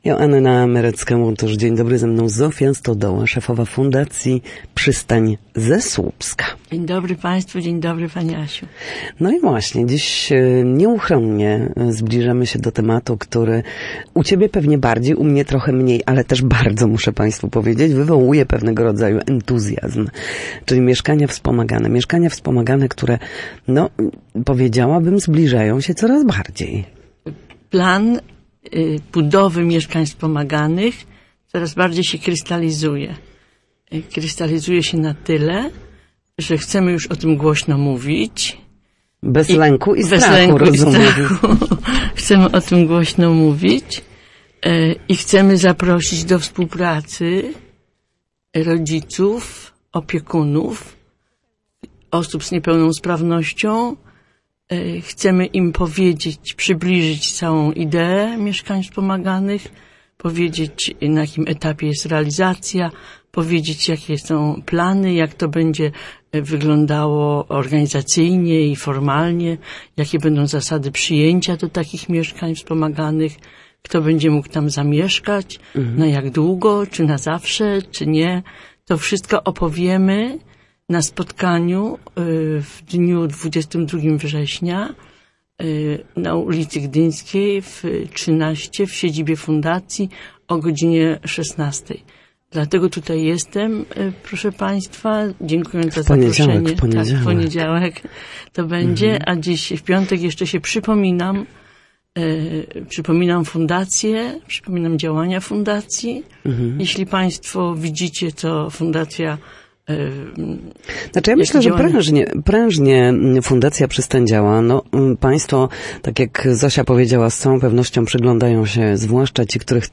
była gościem Studia Słupsk Radia Gdańsk. Fundacja realizuje projekt budowy mieszkań wspomaganych dla dorosłych osób z niepełnosprawnością intelektualną oraz w spektrum autyzmu. Inicjatywa ma na celu wspieranie ich samodzielności i niezależności w codziennym życiu.